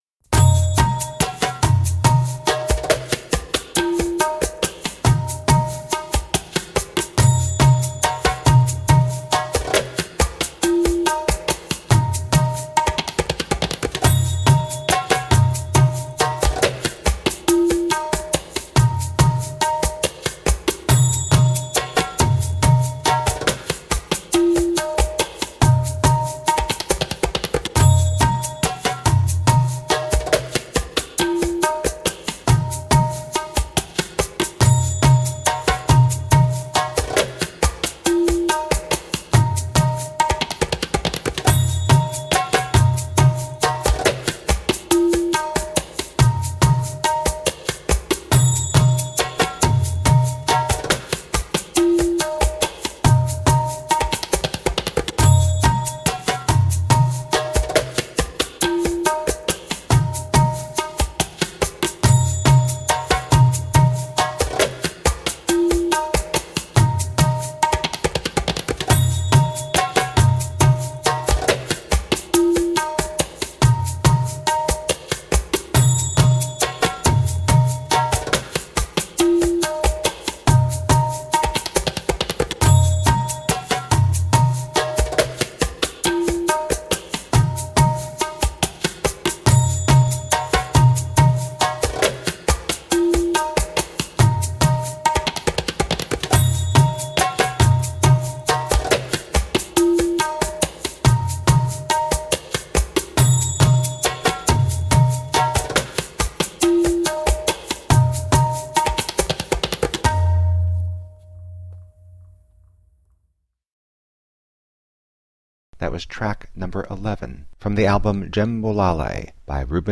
Passionate eastern percussion.
Tagged as: World, Loops, Arabic influenced